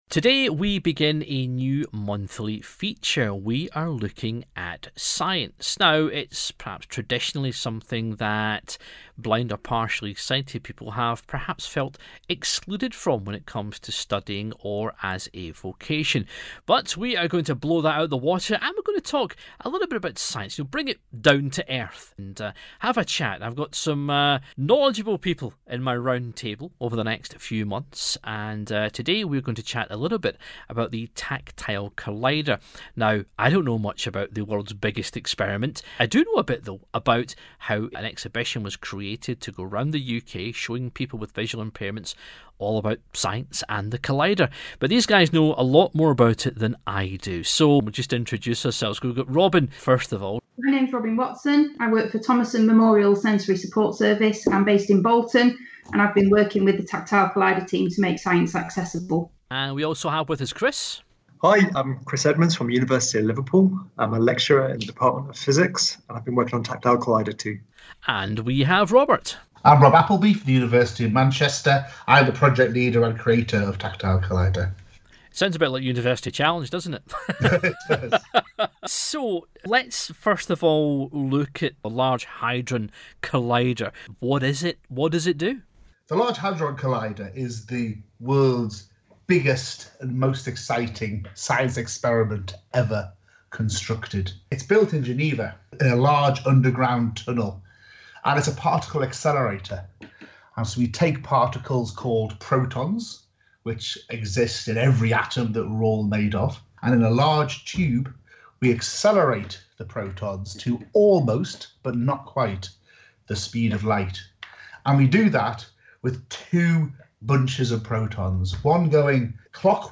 speaks to the experts